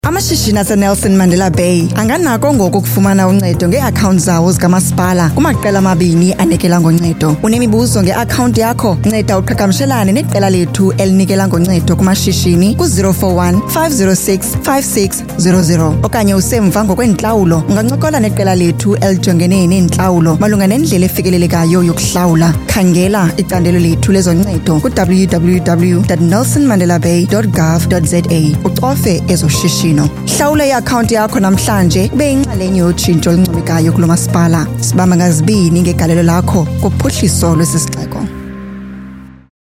articulate, authoritative, bright, commercial, confident, conversational, energetic, informative
Her voice has a unique or rare natural warmth, that can capture the attention of the audience.
Conversational